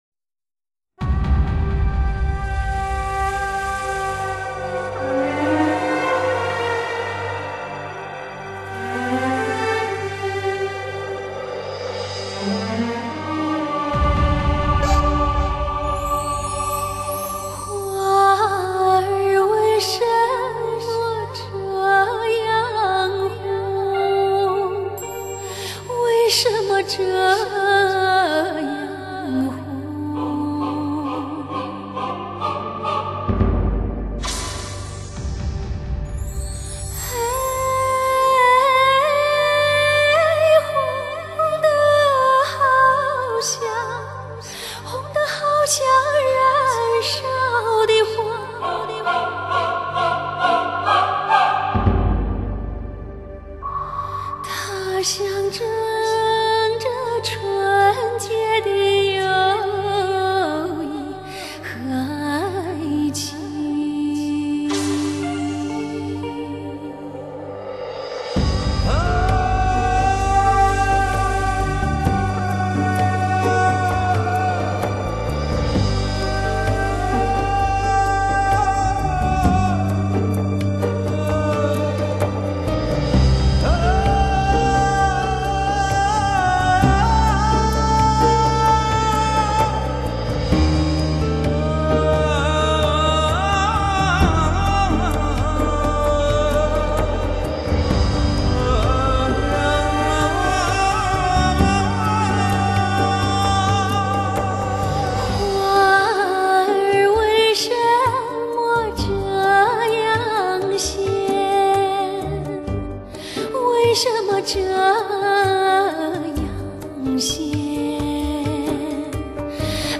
融民族、美声、通俗唱法的跨界女声“晶”美绝伦的声音，层叠放送“晶”心情歌，质感空灵。
或委婉，或奔放，或朴实，或空灵……